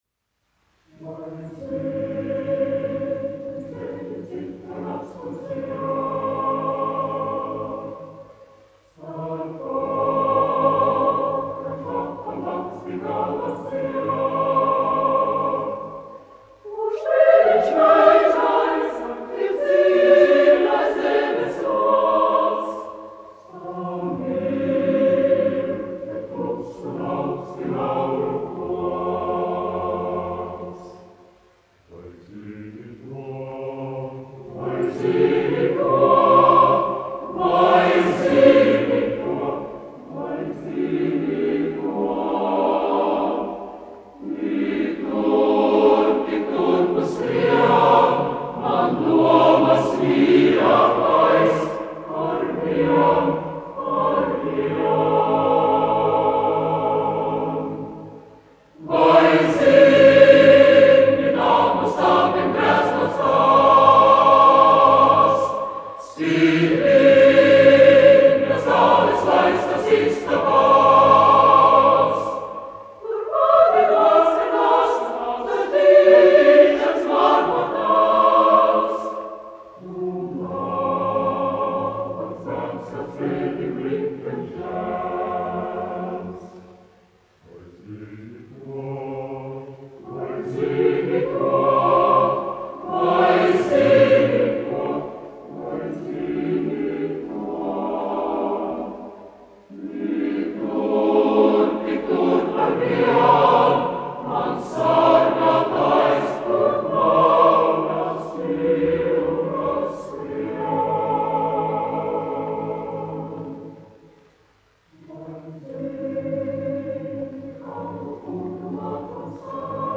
Valsts Akadēmiskais koris "Latvija", izpildītājs
1 skpl. : analogs, 78 apgr/min, mono ; 25 cm
Kori (jauktie)
Latvijas vēsturiskie šellaka skaņuplašu ieraksti (Kolekcija)